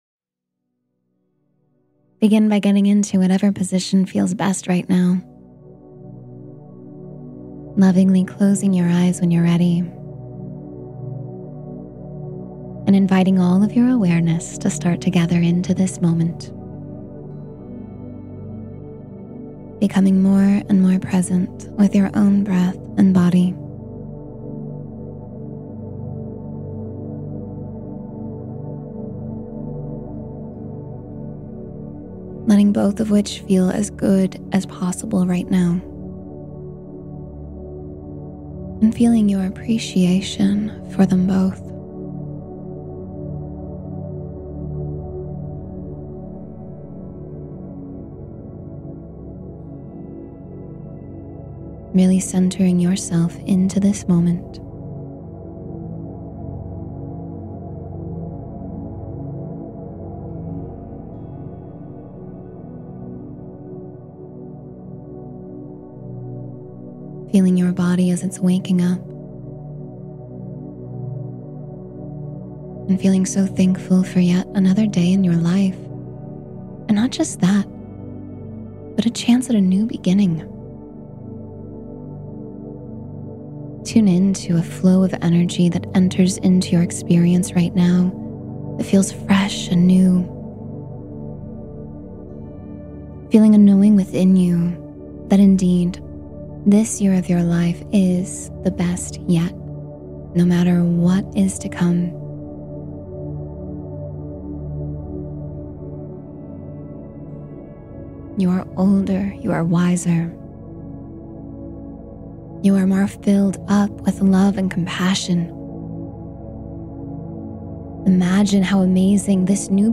Deep Mind and Body Relaxation — Guided Meditation for Full Rejuvenation